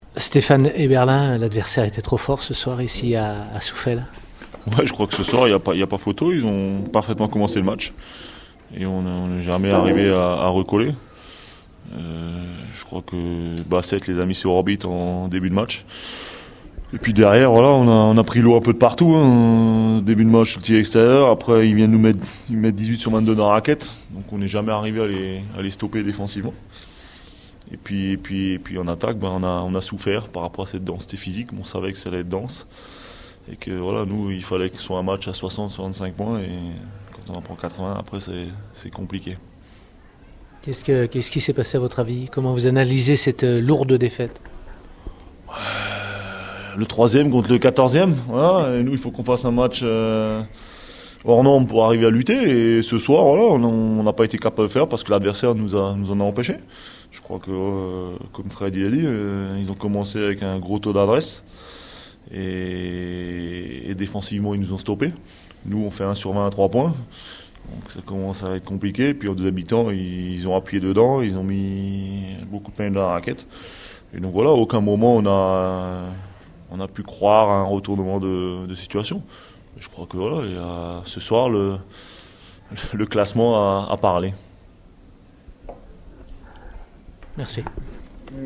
Retrouvez les réactions d’après-match au micro Radio Scoop